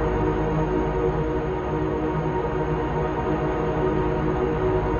bgloop.wav